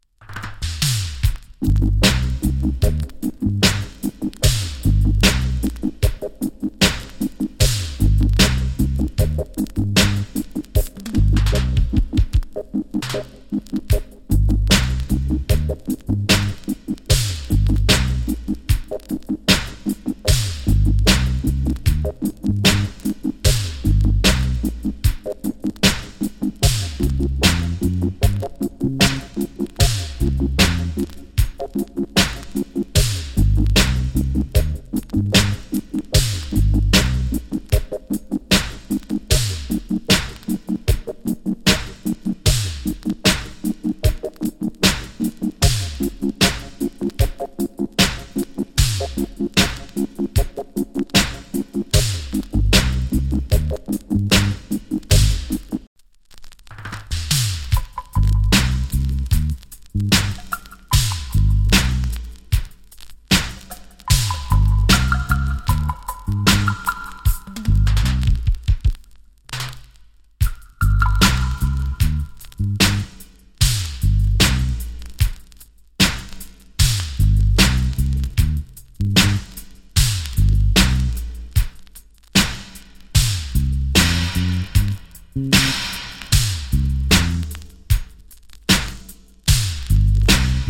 FINE INST.